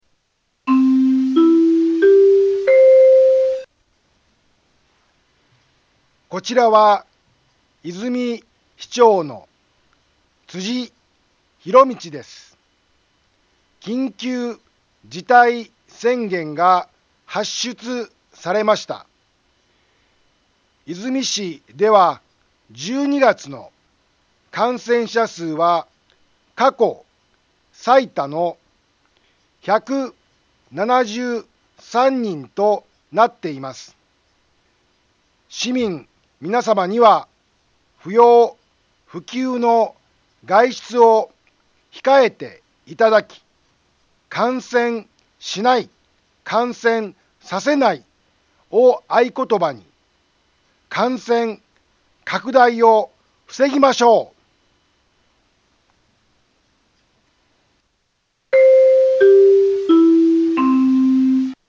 BO-SAI navi Back Home 災害情報 音声放送 再生 災害情報 カテゴリ：通常放送 住所：大阪府和泉市府中町２丁目７−５ インフォメーション：こちらは、和泉市長の辻 ひろみちです。 緊急事態宣言が発出されました。 和泉市では１２月の感染者数は過去最多の１７３人となっています。